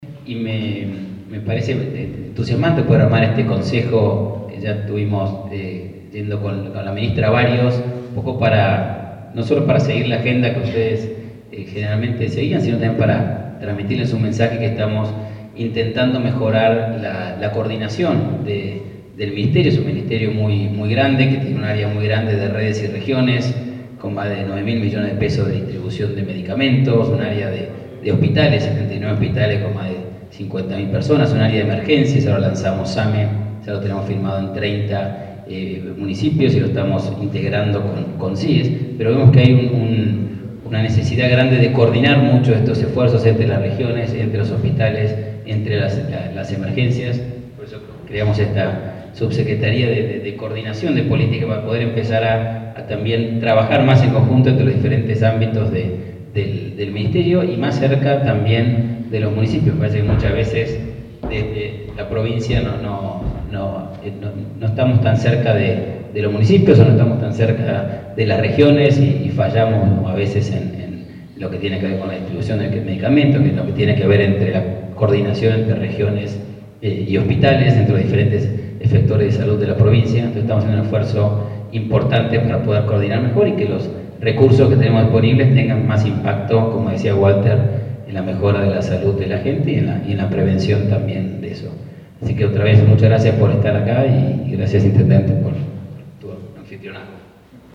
Gabriel Sánchez Zinny Sub-Secretario Regional y Coordinador de Políticas Sanitarias también dió la bienvenida a todos los asistentes al 2º Consejo Regional de Salud.
Gabriel-Sanchez-Zinny-Consejo-Regional-de-Salud.mp3